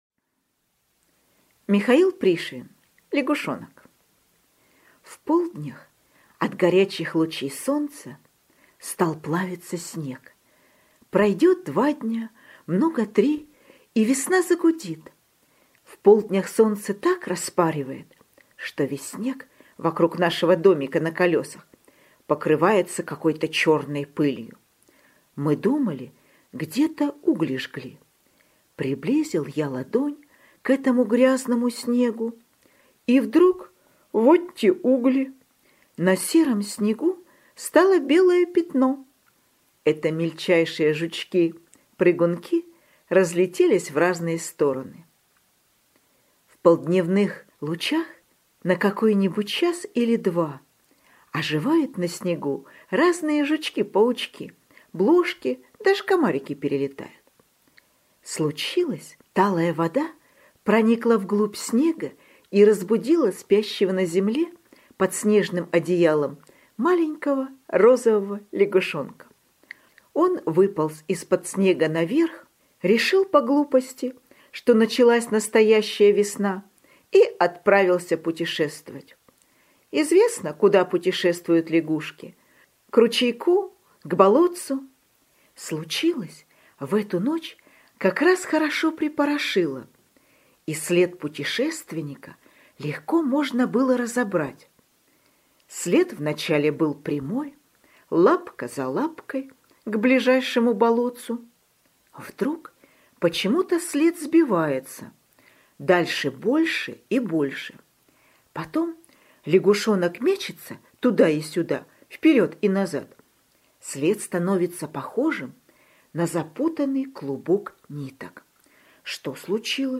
Лягушонок – Пришвин М.М. (аудиоверсия)